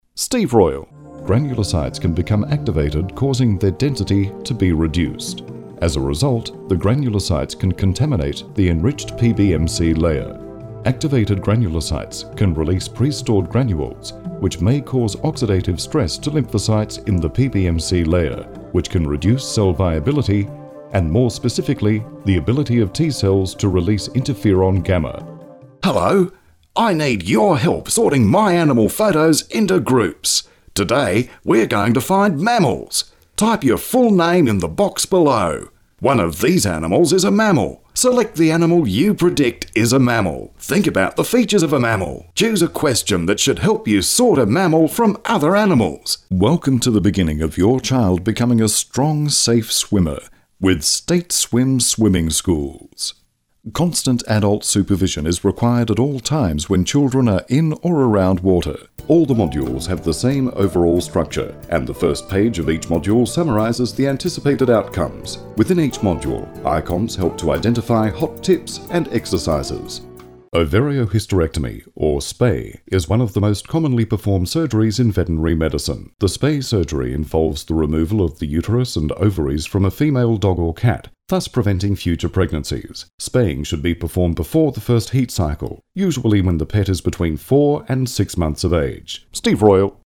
Strong, crisp,versatile, convincing, authoritative, cheeky voice artist
englisch (australisch)
Sprechprobe: eLearning (Muttersprache):